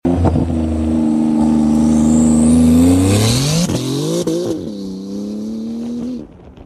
JCR 2RS ROLLING LAUNCH 😮‍💨 sound effects free download